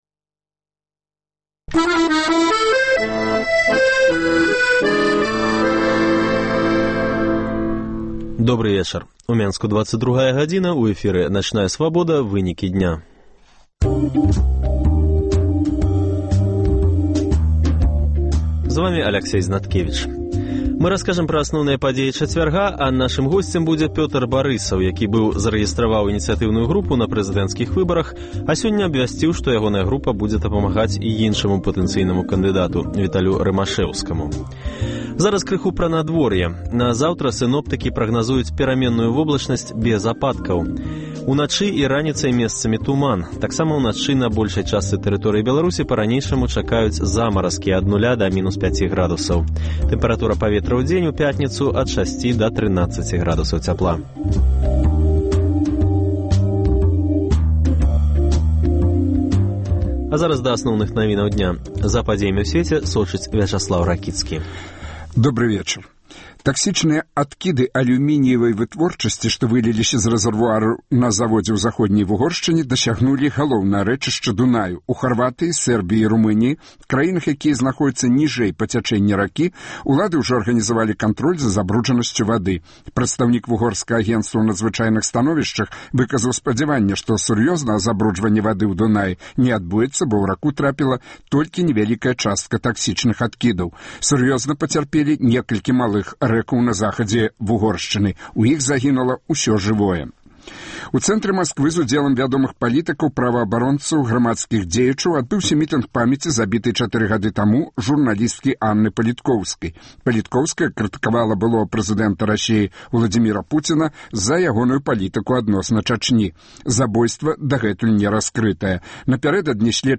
Вечаровы госьць, сацыяльныя досьледы, галасы людзей